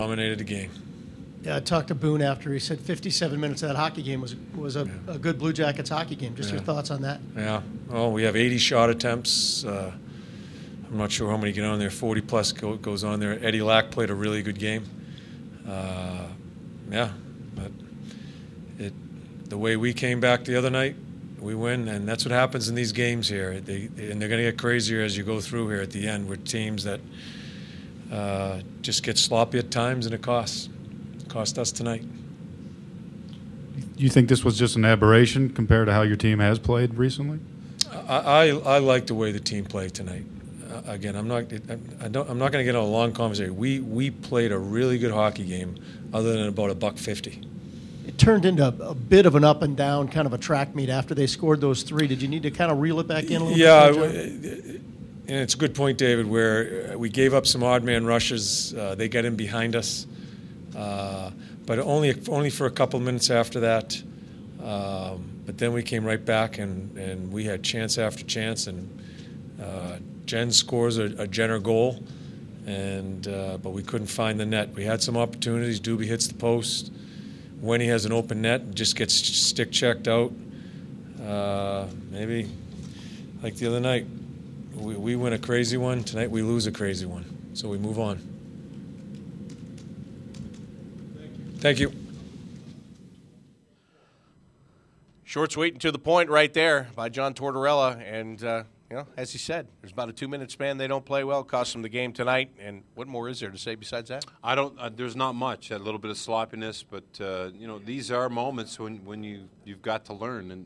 Head Coach John Tortorella addresses media after 3-2 loss to the Carolina Hurricanes 3-24-16